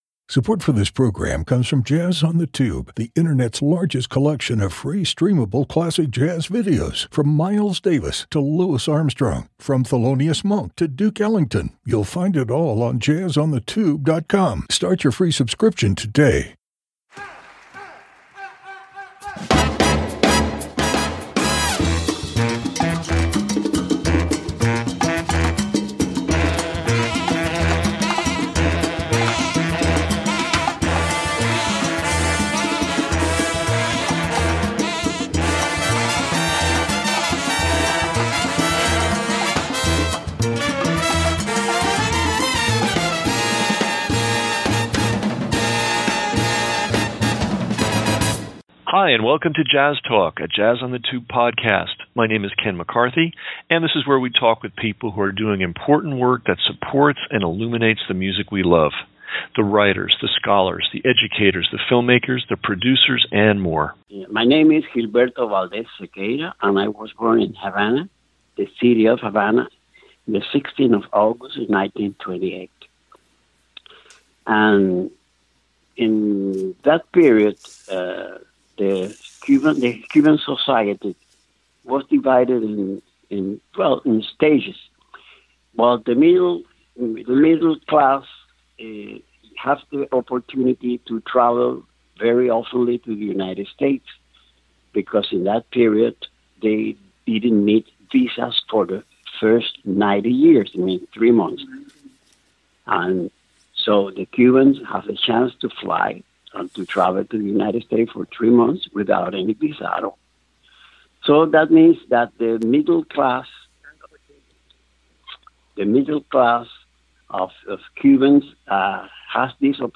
Afro-Cuban culture, Artist-Educators, Blog, Cuba, Cuban Jazz, Jazz on the Tube Interview, Podcasts